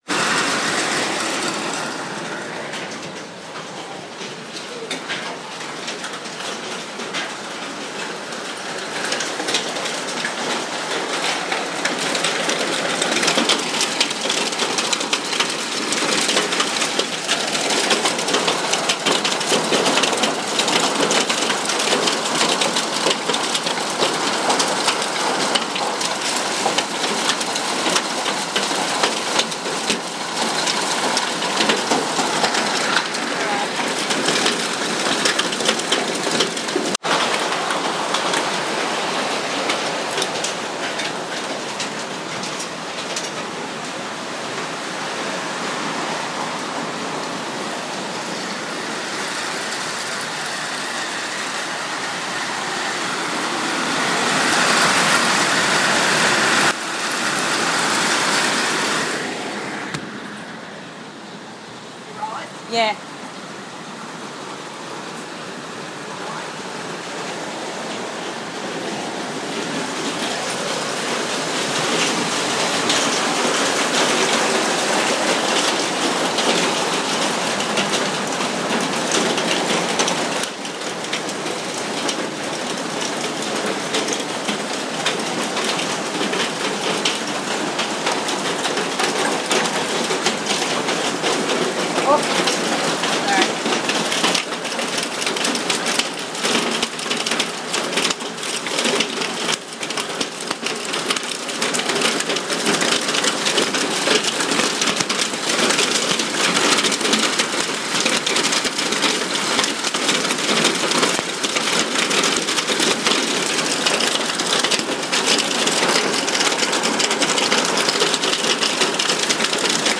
A typical Aussie summer hale storm, this one was wild. My yard was apparently white with ice.
This one was recorded from inside, although you wouldn't no it from the sound. I'm Scurrying around closing doors and windows, so the sound changes quite a bit.